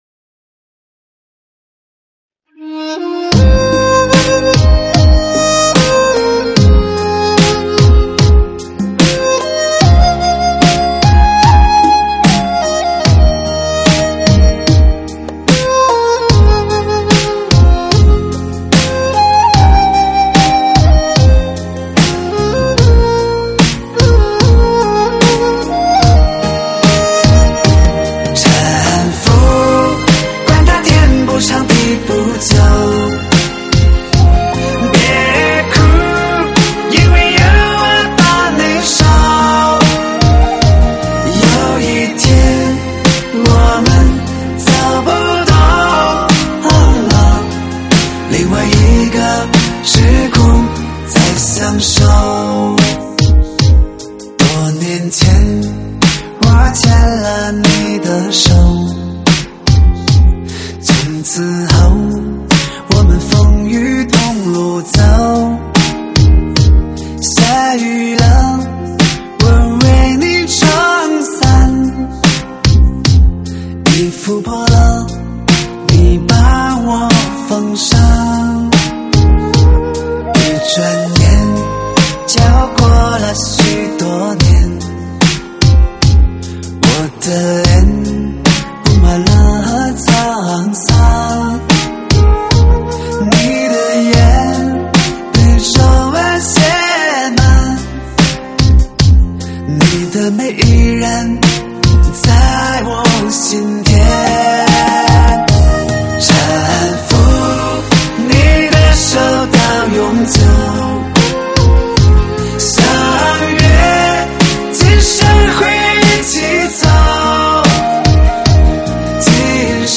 舞曲类别：现场串烧